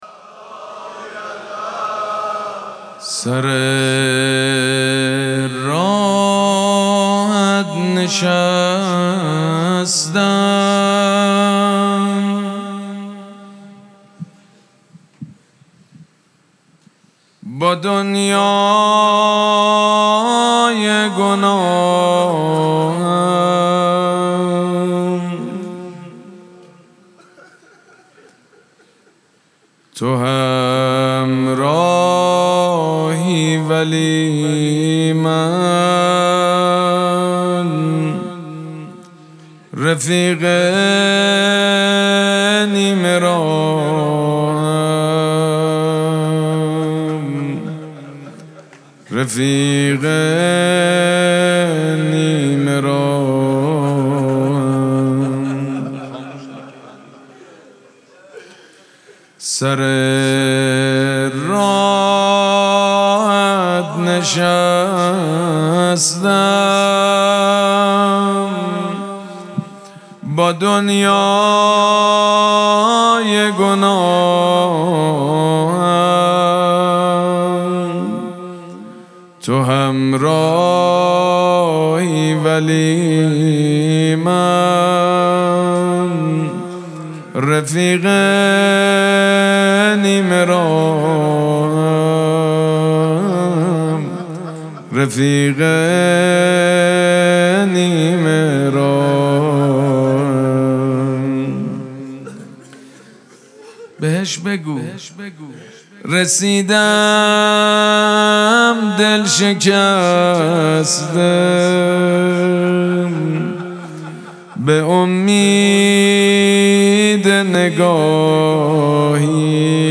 مراسم مناجات شب نوزدهم ماه مبارک رمضان
حسینیه ریحانه الحسین سلام الله علیها
مناجات
حاج سید مجید بنی فاطمه